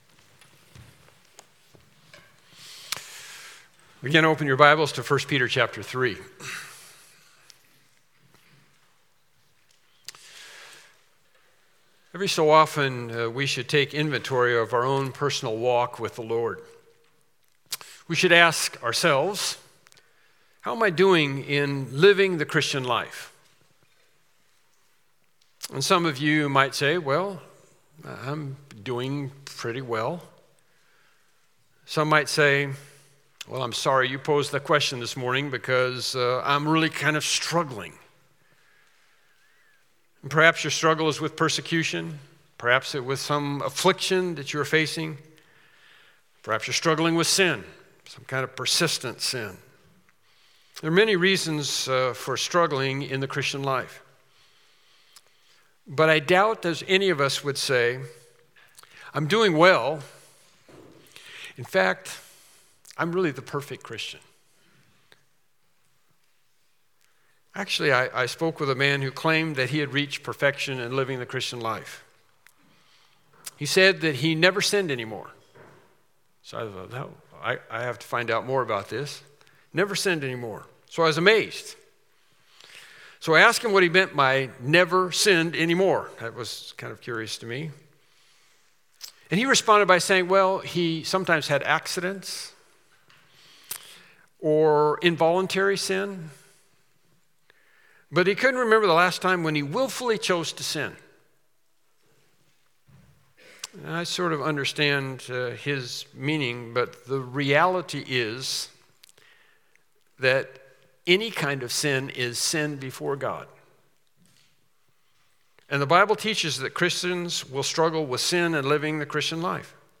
1 Peter 3:21-22 Service Type: Morning Worship Service Topics